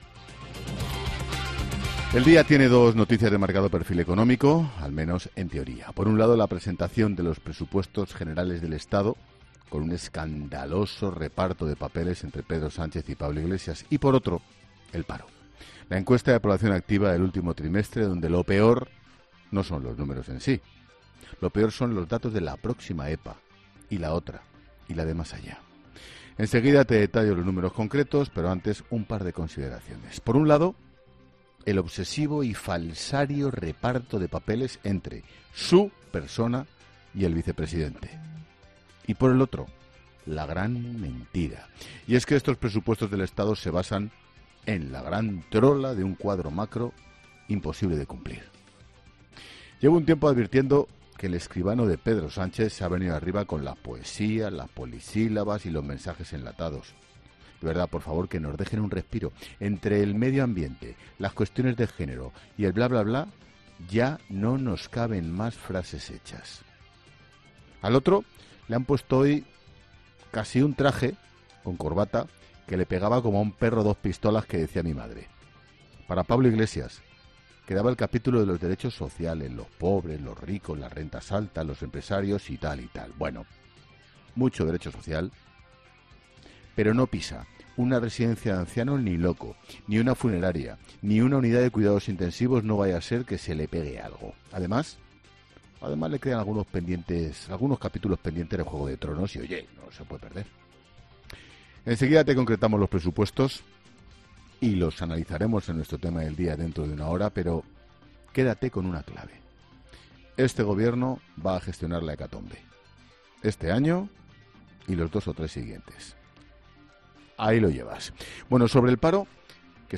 Monólogo de Expósito
El director de 'La Linterna', Ángel Expósito, hace un repaso por los asuntos de actualidad de hoy